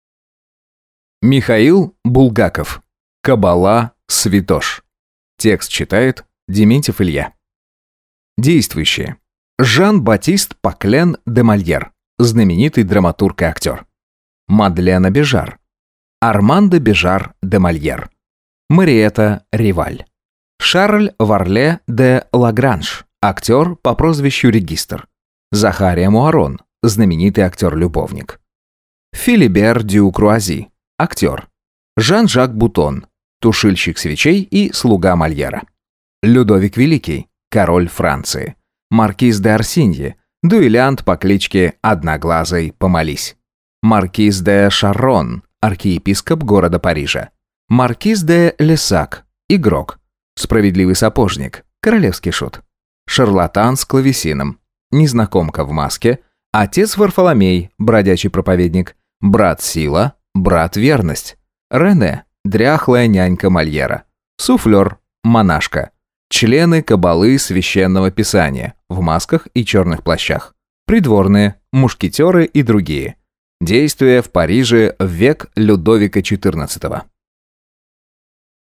Аудиокнига Кабала святош | Библиотека аудиокниг